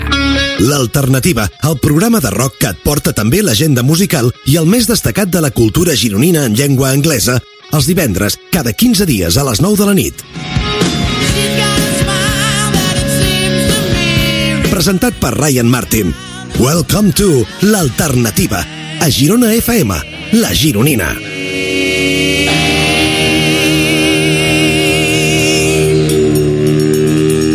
Promoció del programa de rock.
Musical